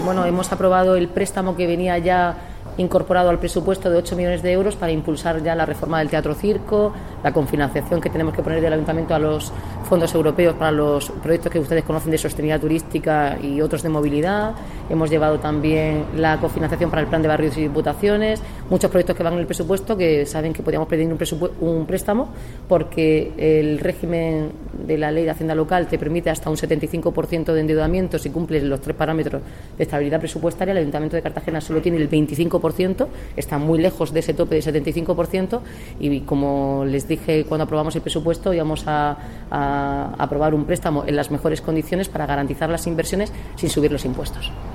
Enlace a Declaraciones de la alcaldesa, Noelia Arroyo, sobre junta de gobierno extraordinaria